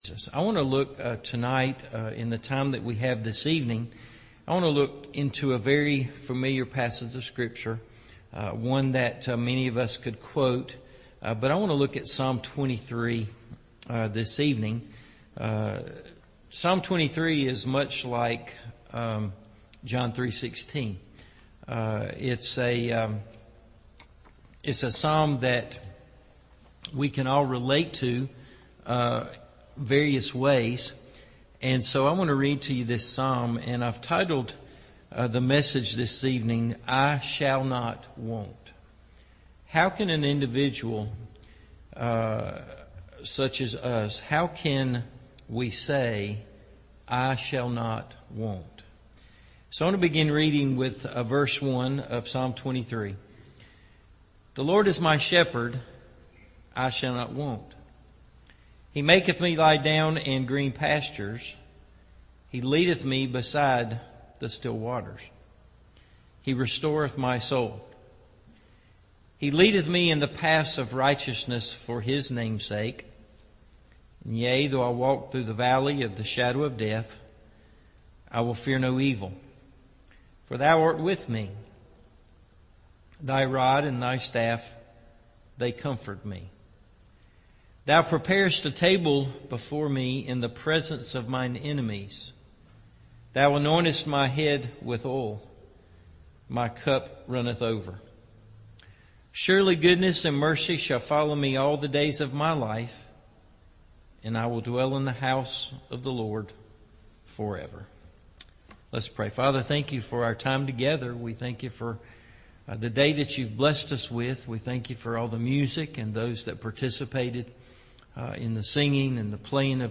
I Shall Not Want – Evening Service